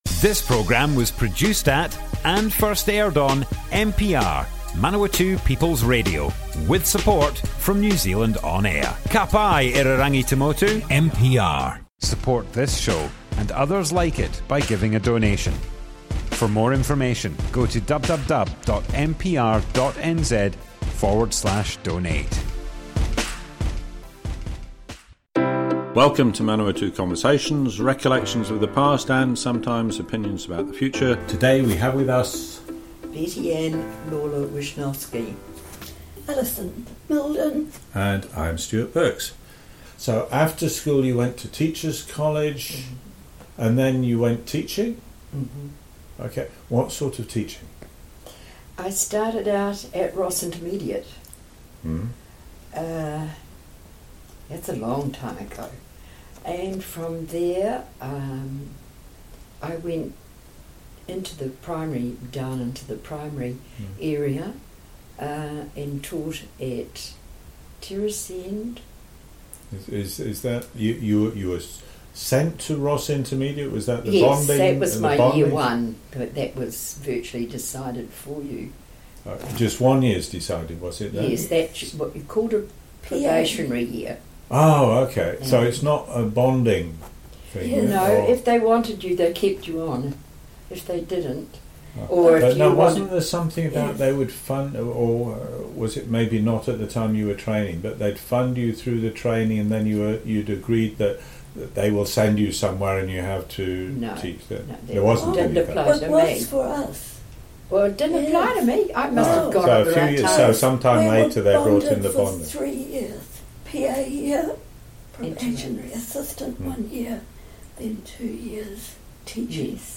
Manawatu Conversations More Info → Description Broadcast on Manawatu People's Radio, 30th March 2021.
oral history